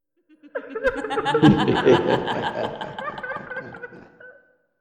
Possessed Laugh (Stereo)
chuckle daemon demon evil female laugh laughing laughter sound effect free sound royalty free Funny